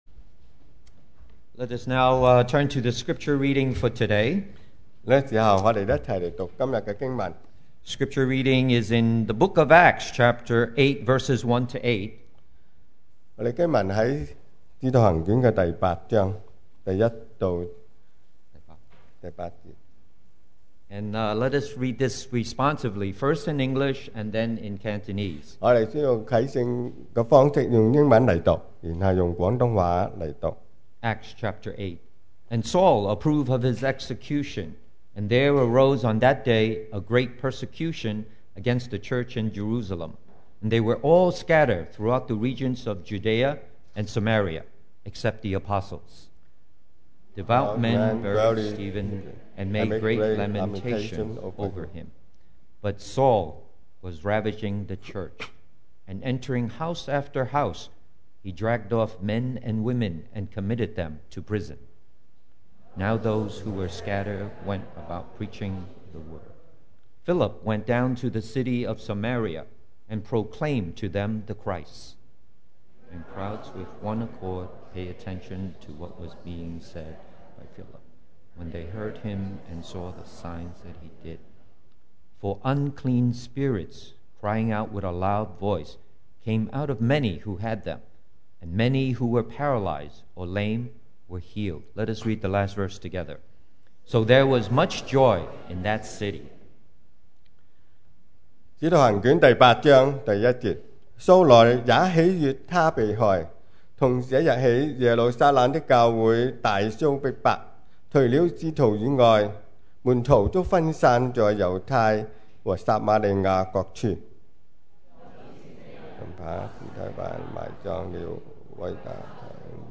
2019 sermon audios
Service Type: Sunday Morning